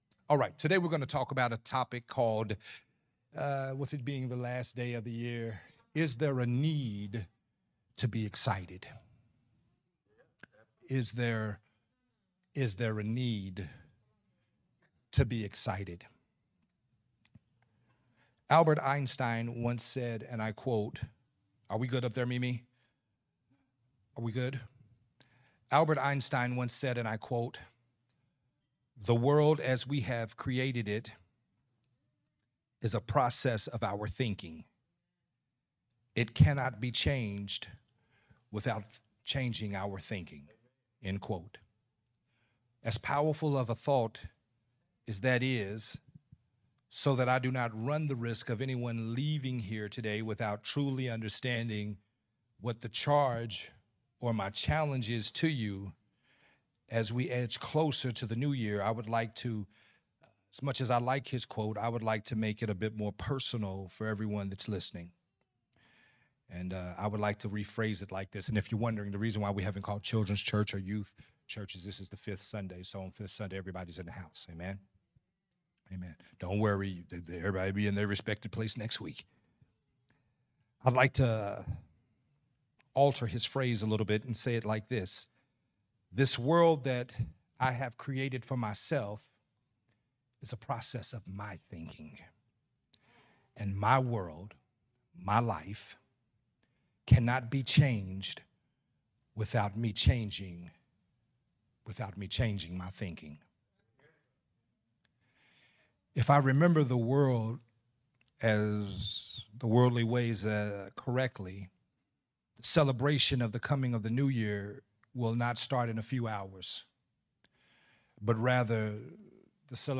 Sunday Morning Worship Service